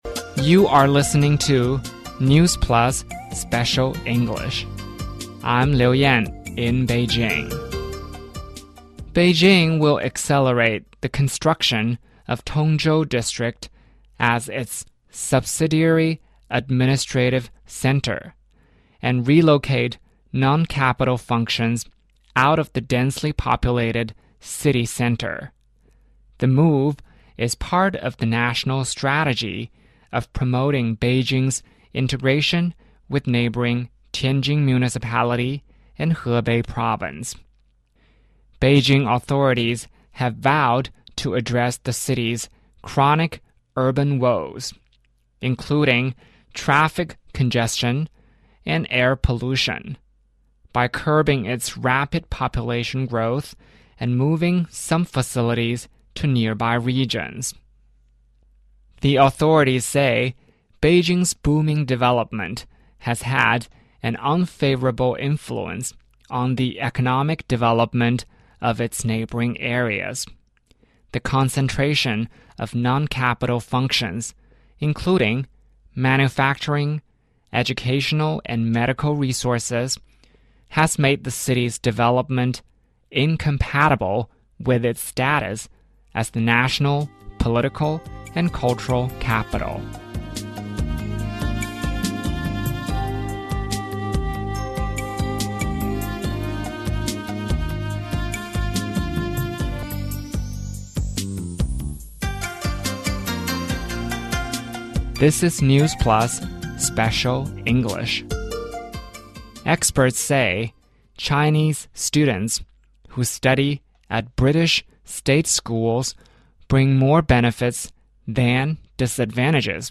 News Plus慢速英语:北京非首都功能疏解全面推进实施 中国学生进军英国公立中学引英国家长不满